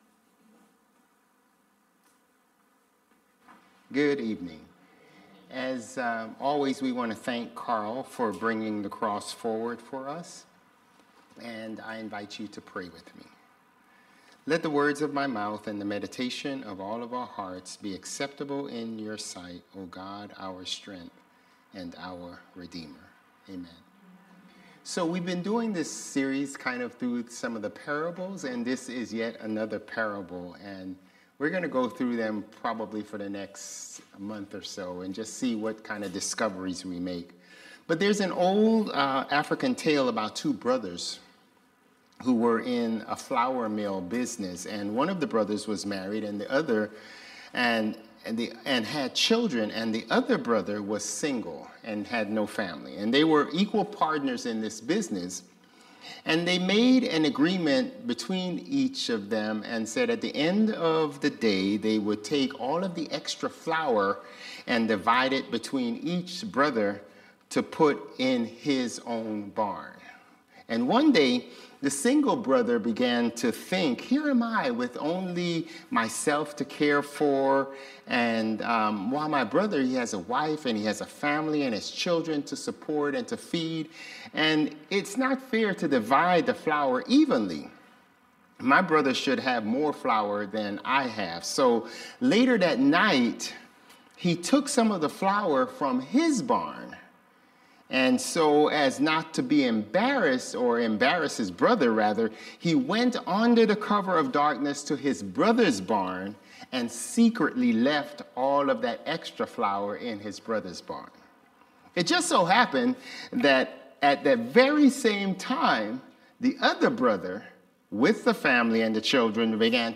Sermons | Bethel Lutheran Church
December 1 Worship